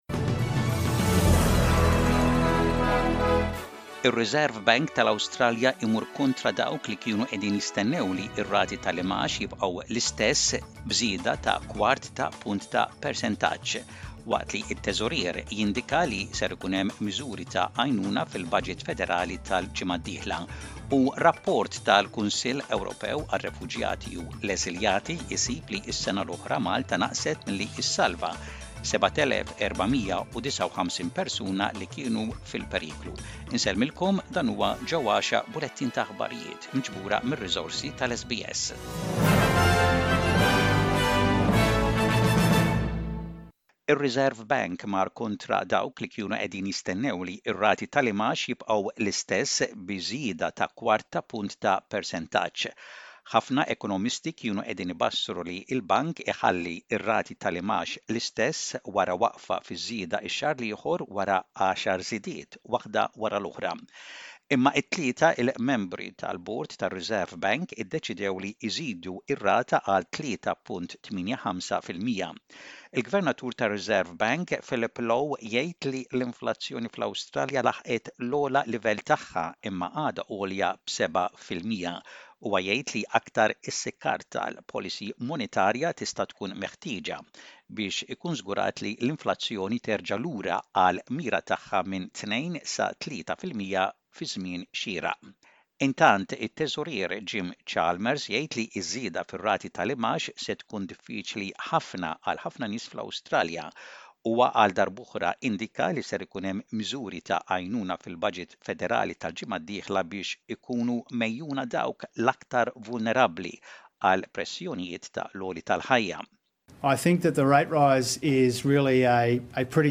SBS Radio | Maltese News: 5/05/23